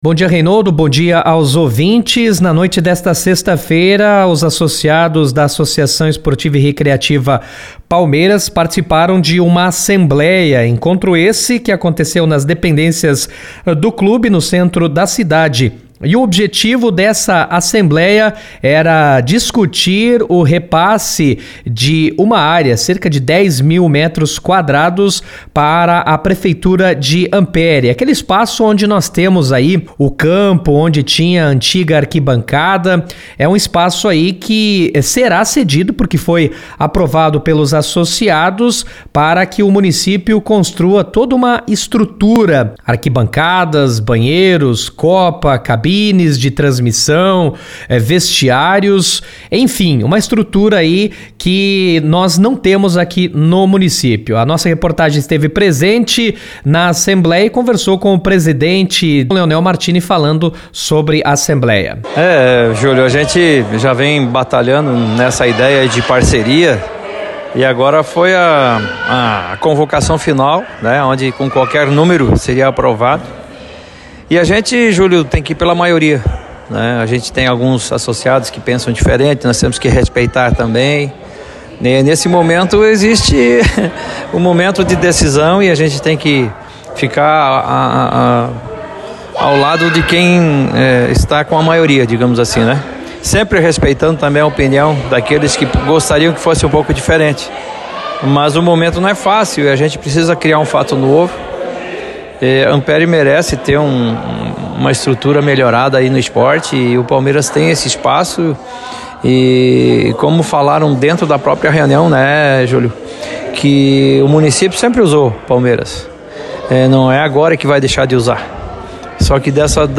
e com o vice-prefeito Celso Saggiorato.